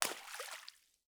SPLASH_Subtle_mono.wav